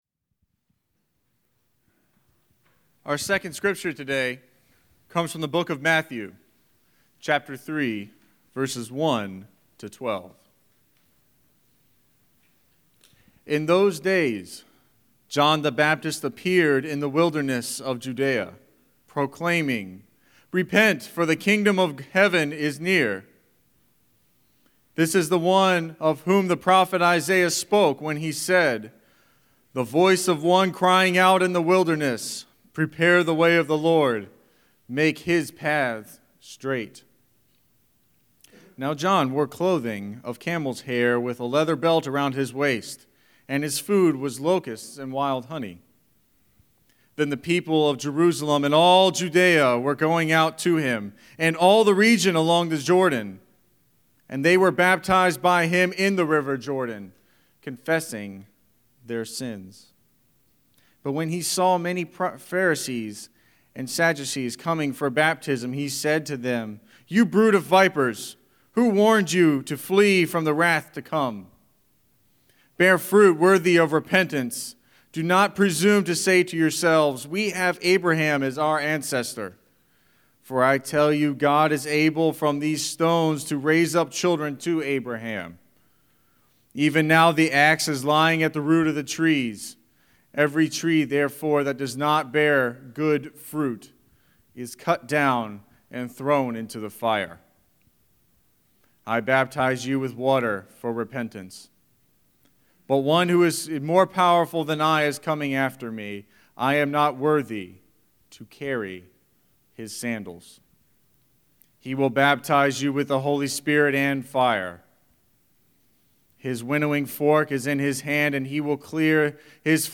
12-13-Scripture-and-Sermon.mp3